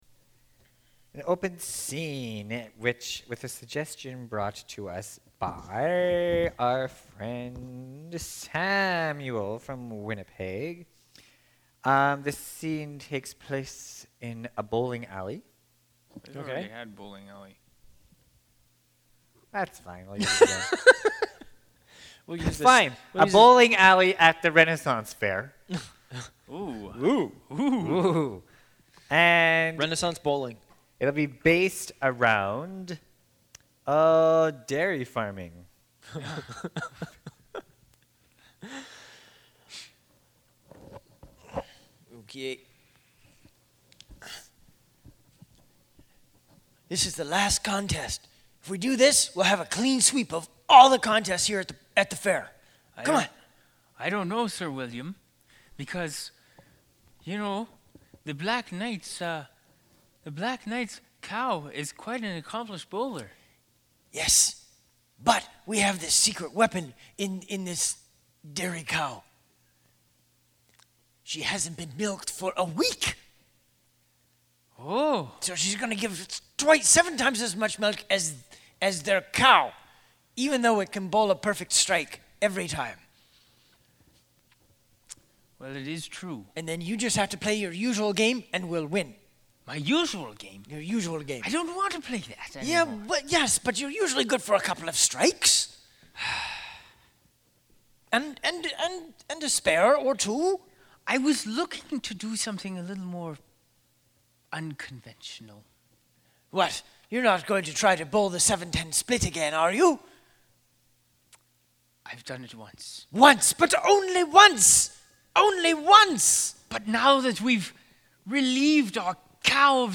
Radio Demo:
A few years ago The Rascals were asked to do some recordings for a radio show.